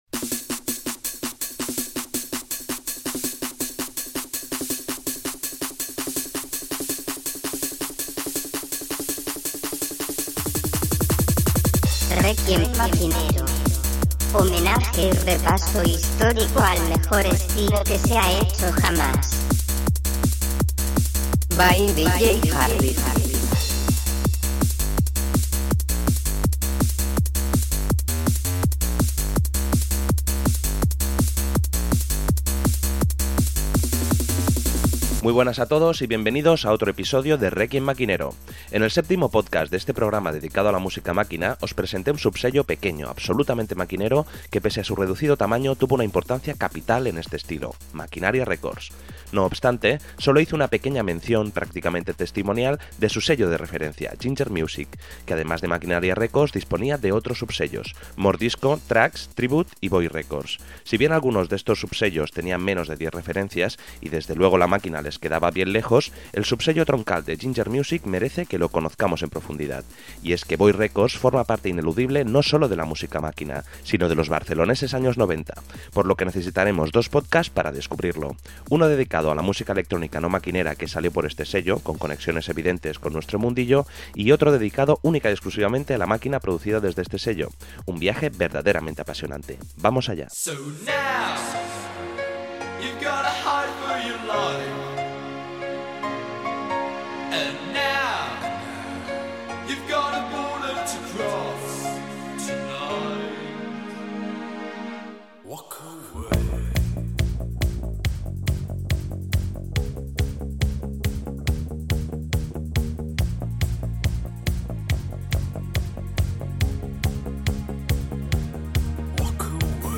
Sesiones y programas de música electrónica